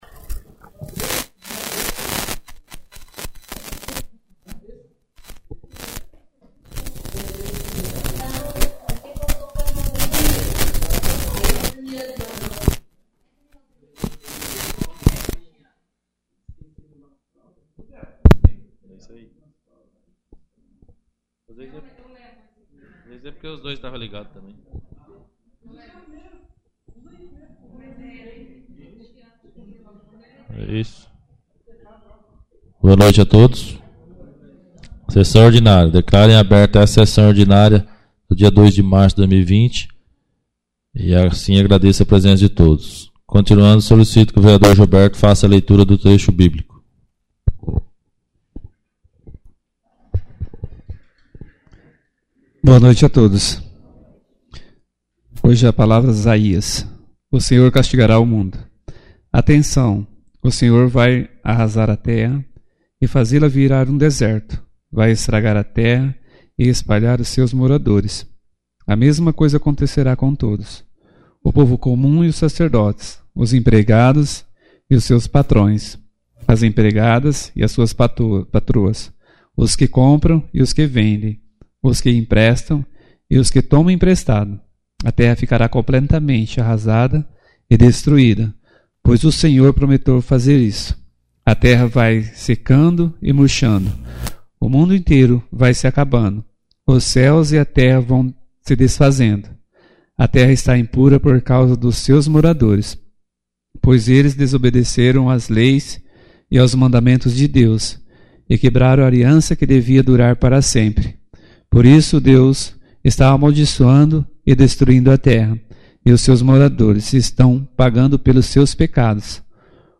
4ª Ordinária da 4ª Sessão Legislativa da 11ª Legislatura